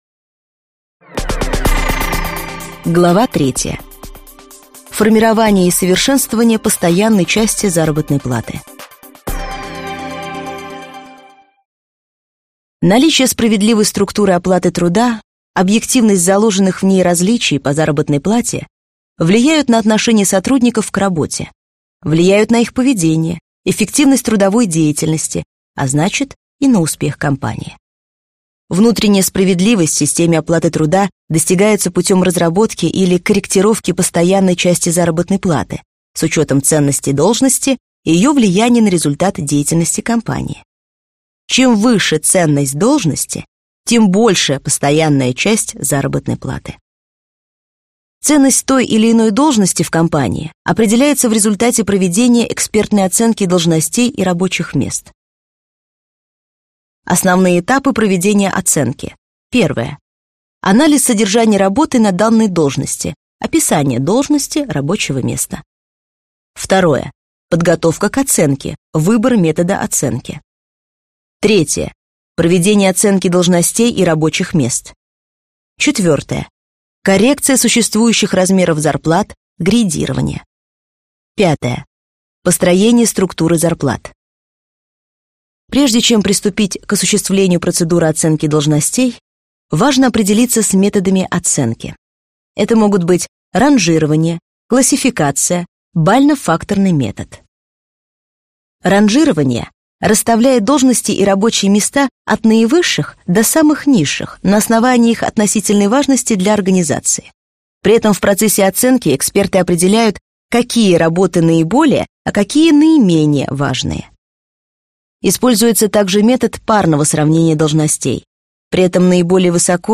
Аудиокнига Мотивация и оплата труда | Библиотека аудиокниг